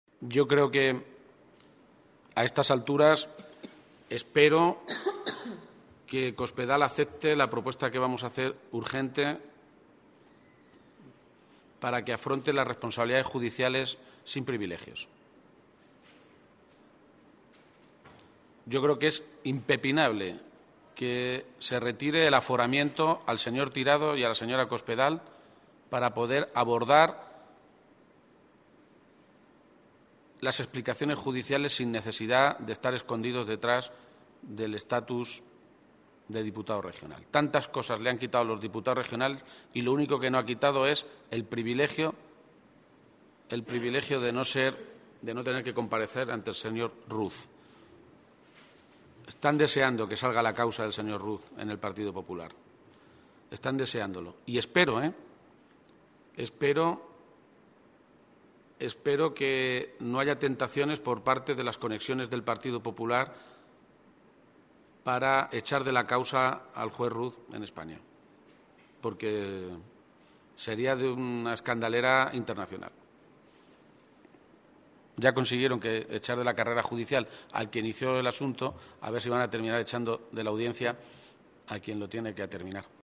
Se pronunciaba así García-Page, esta mañana, en Toledo, a preguntas de los medios de comunicación, que le pedían una valoración sobre las informaciones publicadas hoy en un medio de comunicación nacional que señalan a que el auto del juez Ruz, conocido el pasado miércoles, sobre la llamada trama Gürtel, acredita otras poisbles mordidas de la empresa Sufi para hacerse con contratos públicos en municipios gobernados por el PP como Madrid, Salamanca o Majadohanda.
Cortes de audio de la rueda de prensa